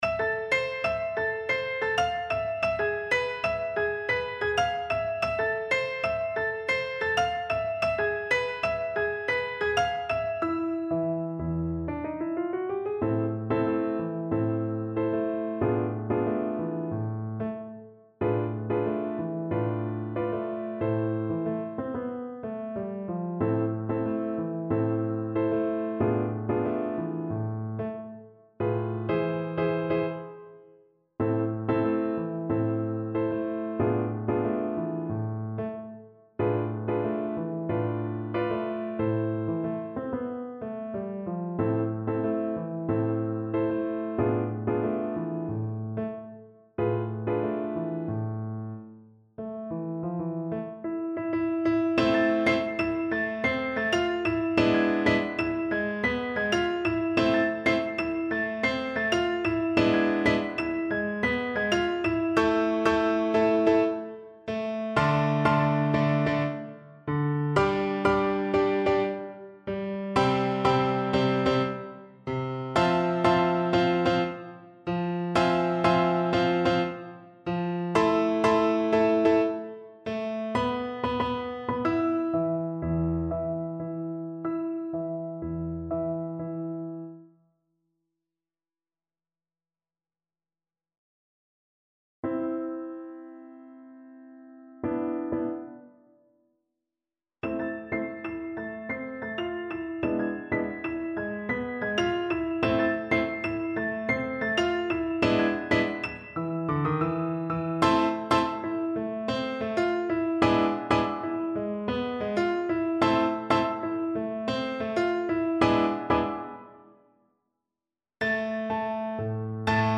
A firey salsa-inspired piece.
Energico =120
Jazz (View more Jazz Violin Music)